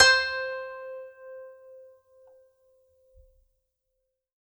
52-str15-zeng-c4.aif